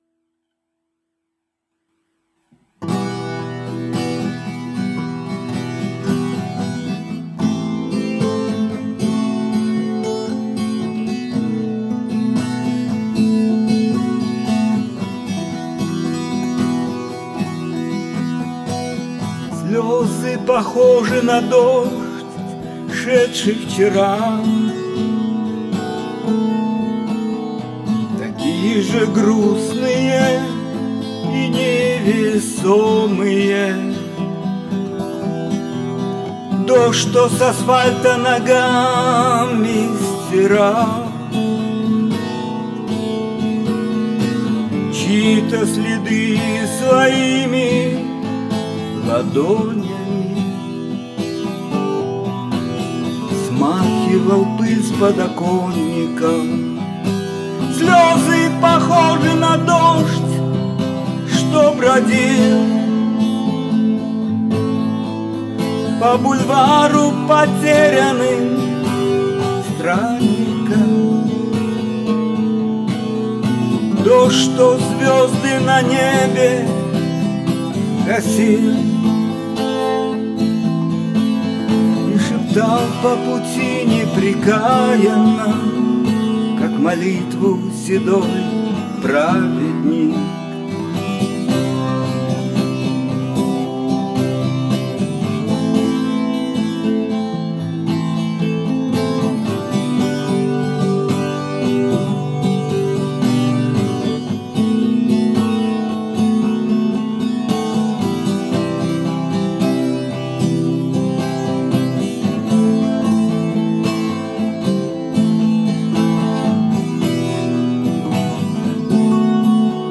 гитара